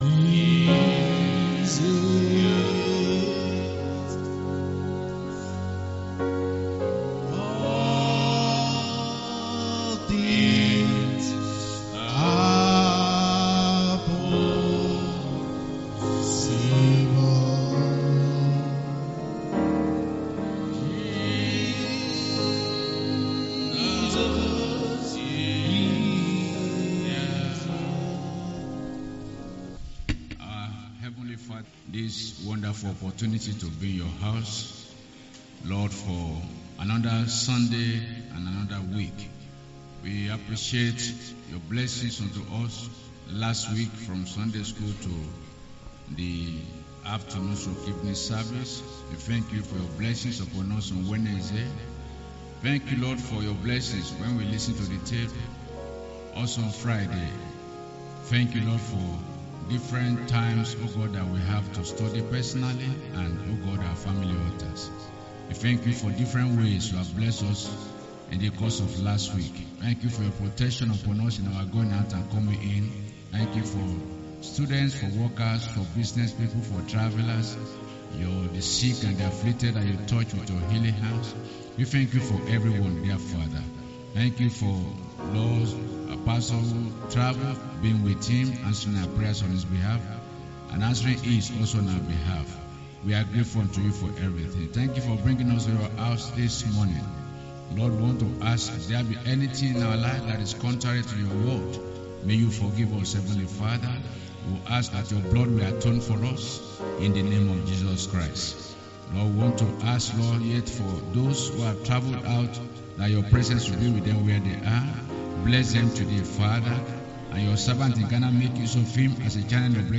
SUNDAY MAIN SERV.